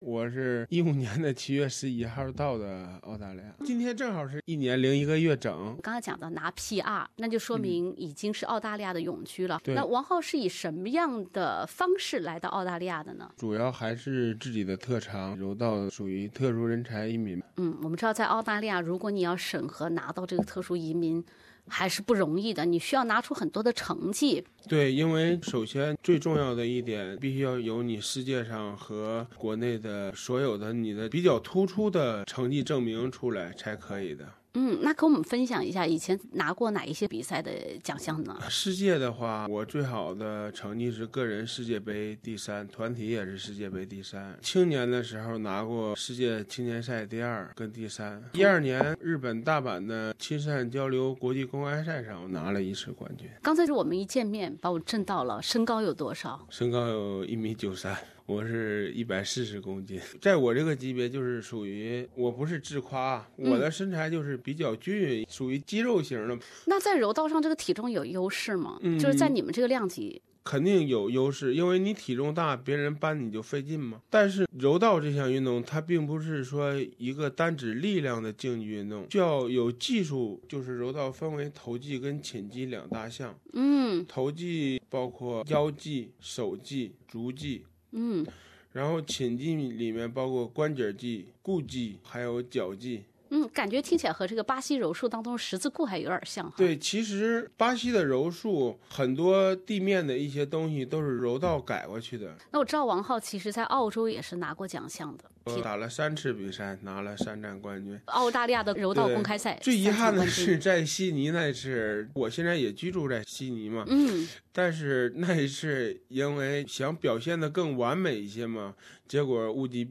专访